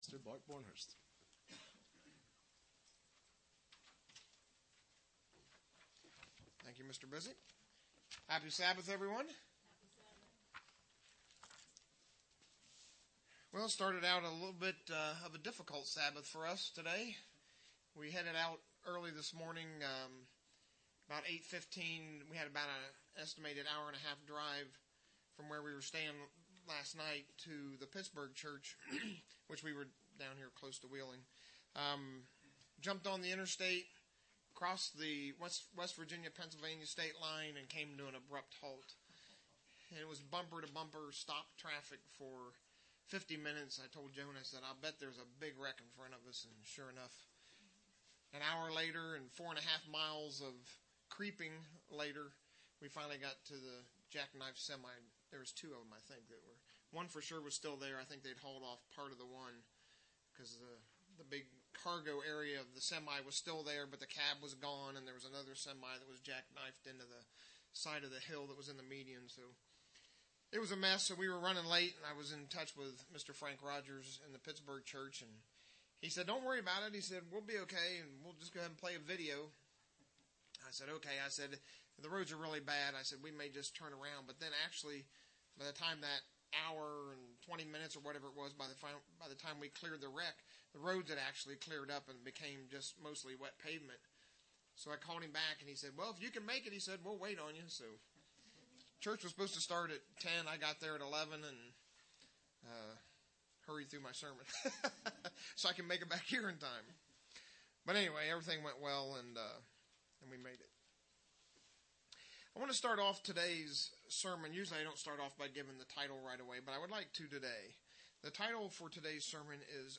Given in Wheeling, WV
UCG Sermon Studying the bible?